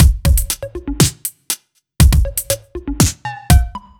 Index of /musicradar/french-house-chillout-samples/120bpm/Beats
FHC_BeatD_120-03.wav